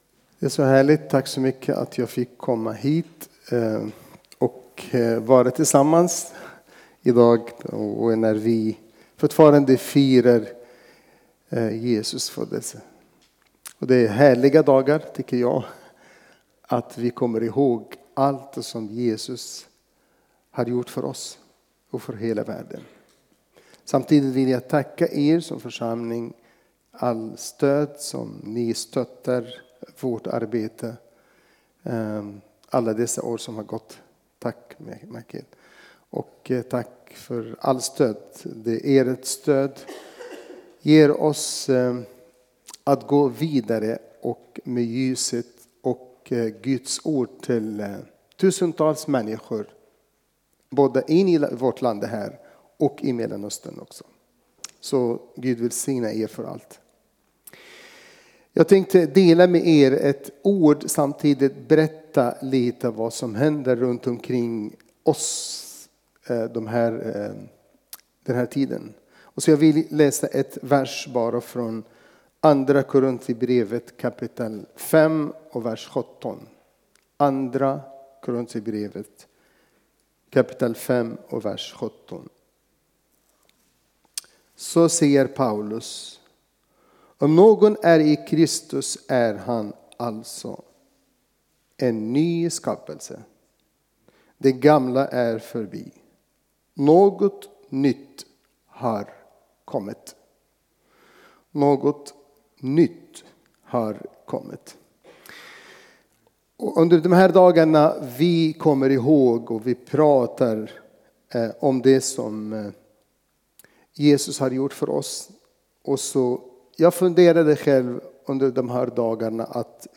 Furuhöjdskyrkans församling, Alunda
Gudstjänst